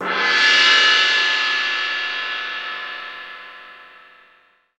Index of /90_sSampleCDs/Roland LCDP03 Orchestral Perc/CYM_Gongs/CYM_Dragon Cymbl